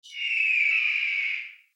Download Eagle sound effect for free.
Eagle